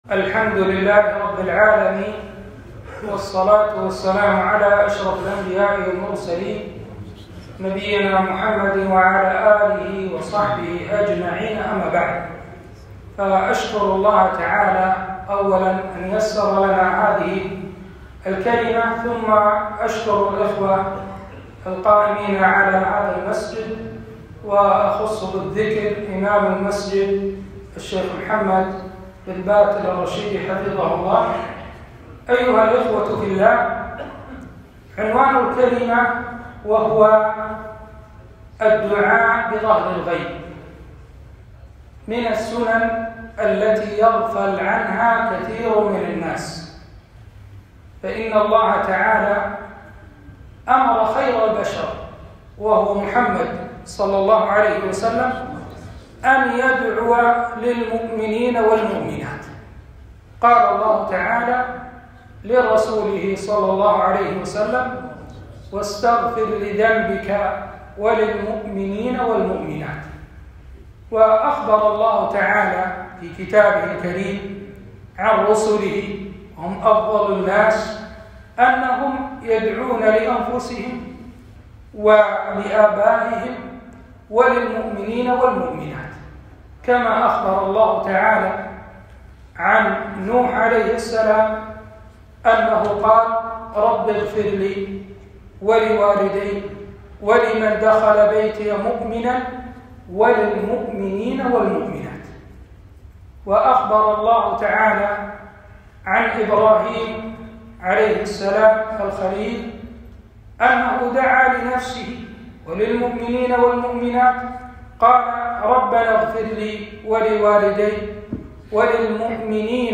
كلمة - الدعاء بظهر الغيب